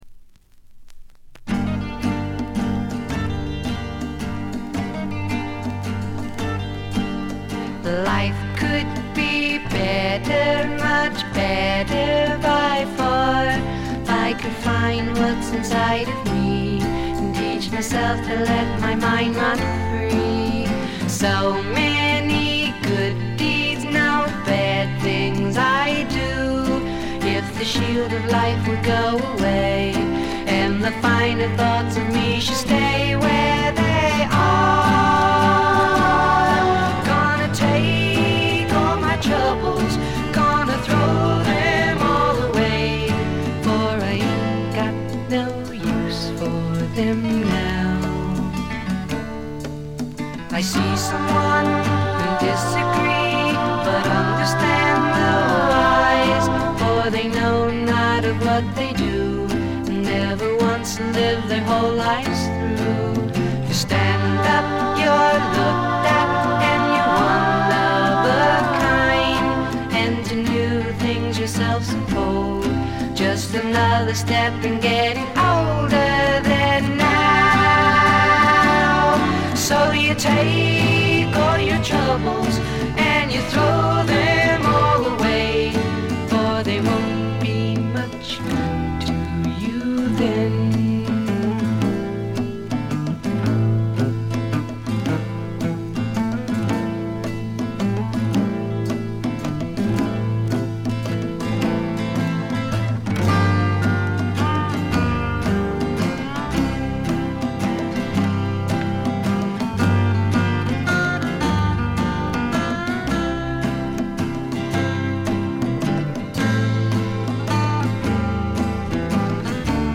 わずかなノイズ感のみ。
試聴曲は現品からの取り込み音源です。